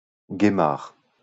Guémar (French pronunciation: [ɡemaʁ]